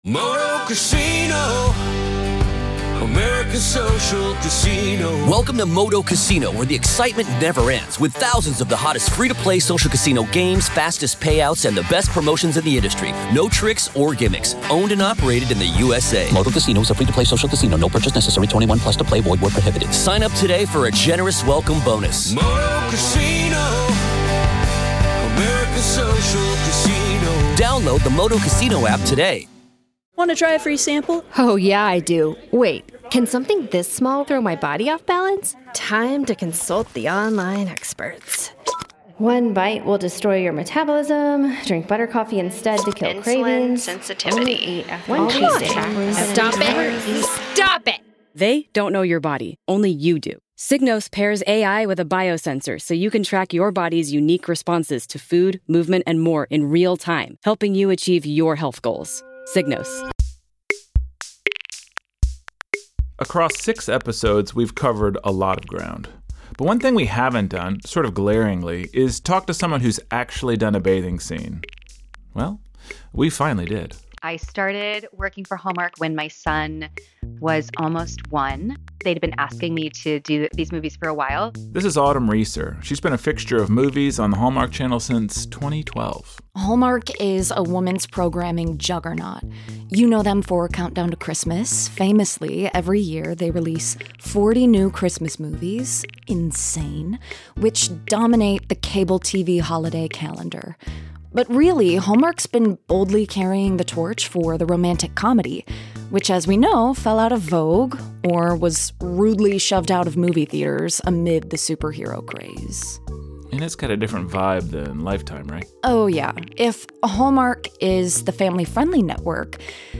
We finally talk to an actress who’s done a tub scene: Autumn Reeser, whose experience in a time-loop movie woke her up to the time loop she was living in her real life.
News clips from CNN’s 1993 report on “Internet,” ABC7 News on loneliness , The Atlantic on sex , and Fox Business on birth rates .